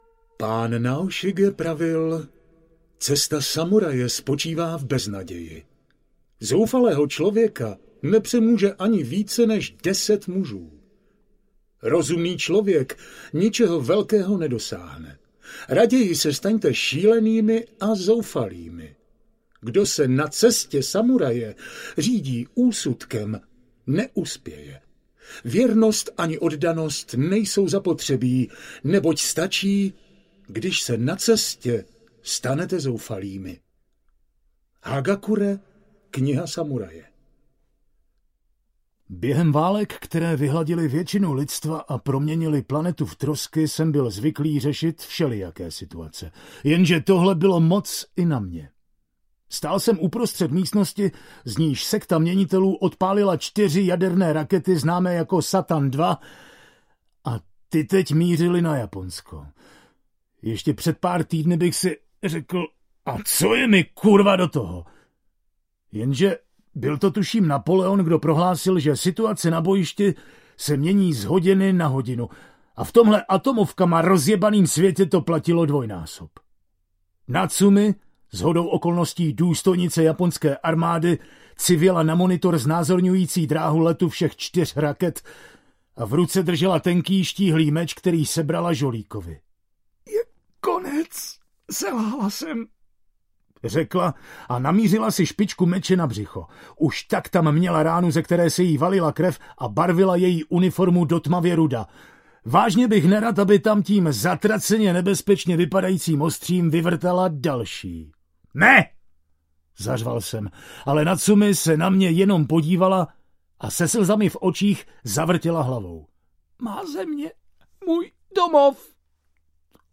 Rázová vlna audiokniha
Ukázka z knihy